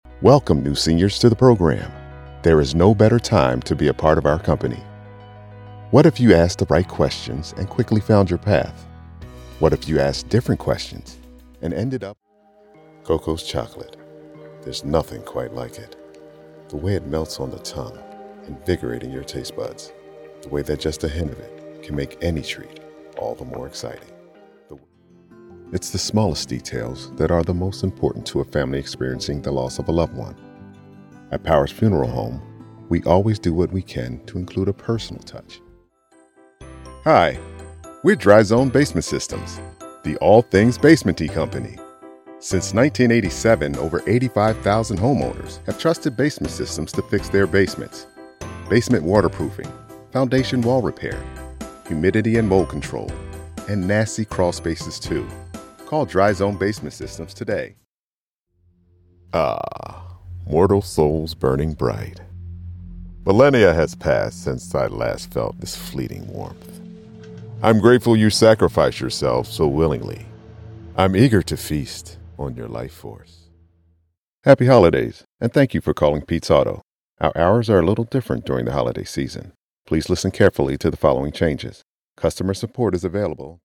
Male voice actor
Sensitive, Sexy, Deep, Characters